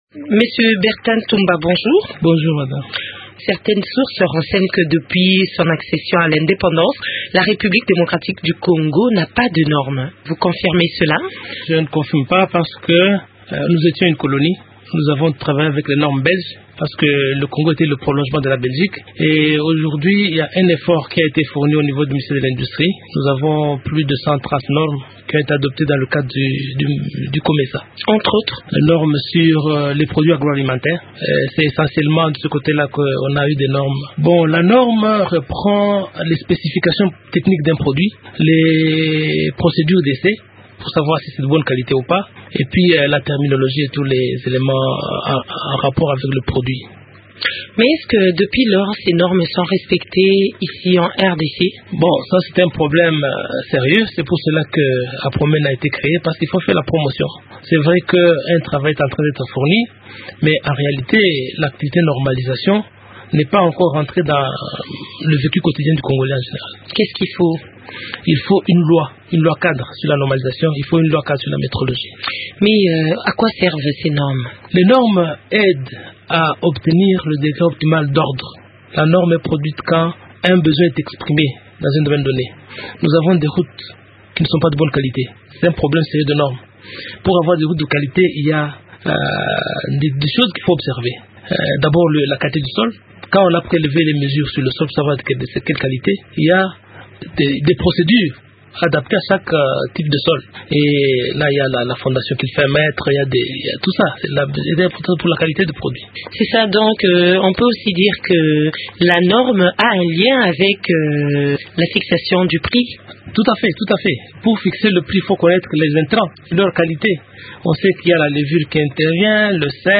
entretient